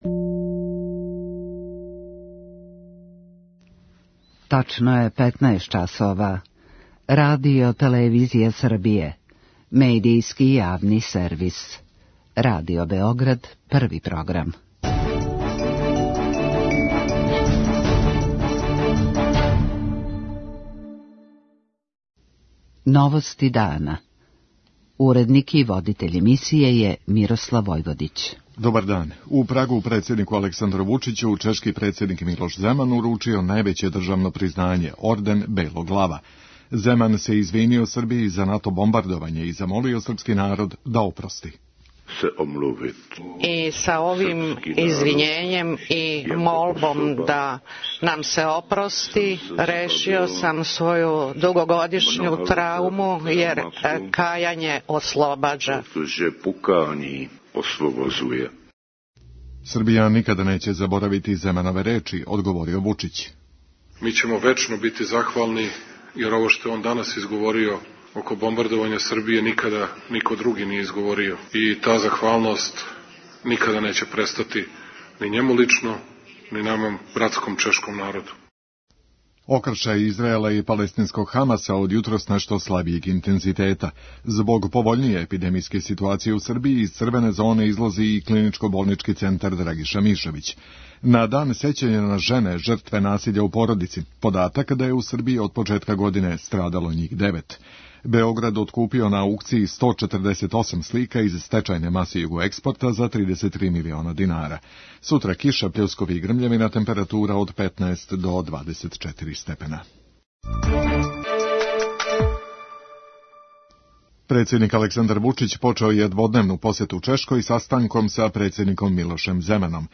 Вучић је навео да ће Срби од сада Чехе доживљавати не само као пријатељски, већ и братски народ. преузми : 6.16 MB Новости дана Autor: Радио Београд 1 “Новости дана”, централна информативна емисија Првог програма Радио Београда емитује се од јесени 1958. године.